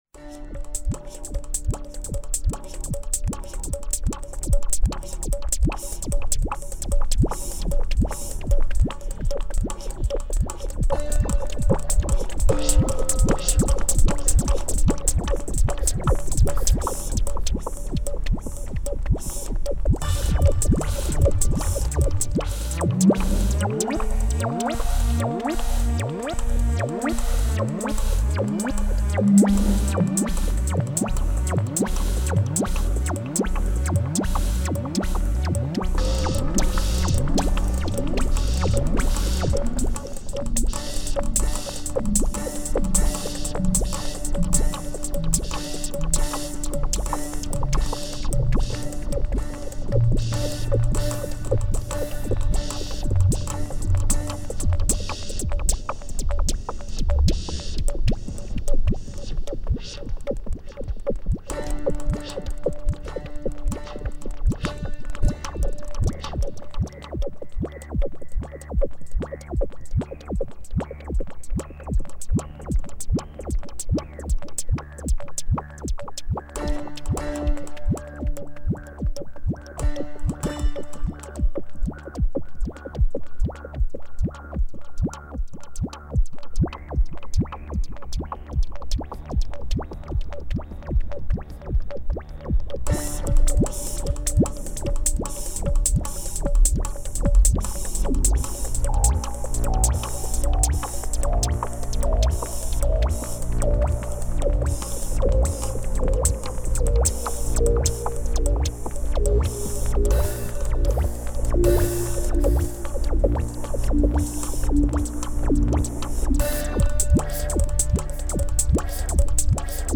Tempo: 150 bpm / Date: 27.10.2018